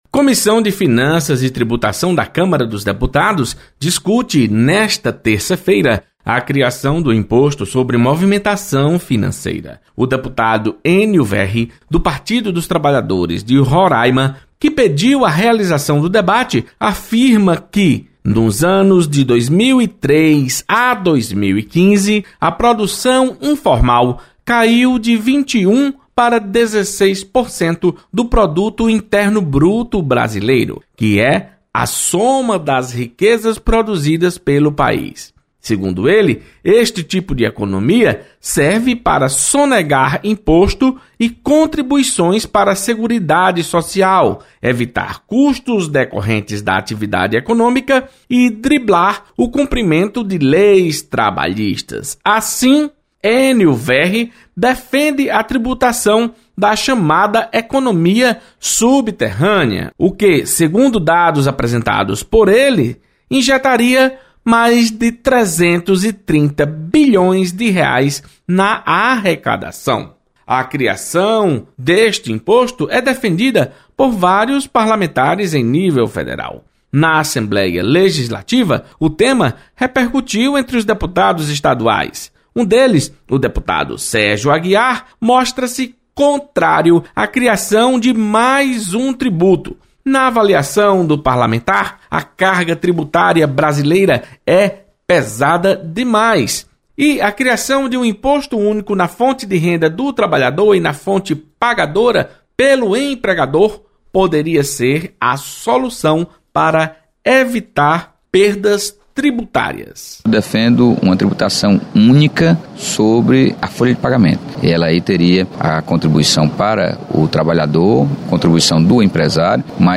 Deputado Sérgio Aguiar critica proposta de criação de imposto sobre movimentação financeira. Repórter